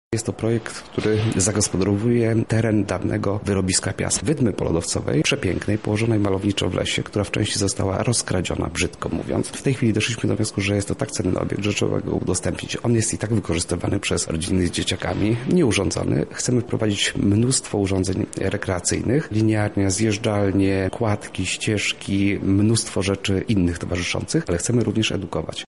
O AMONICIE, leśnym zespole rekreacyjno-edukacyjnym mówi Mariusz Osiak, wójt Gminy Łuków: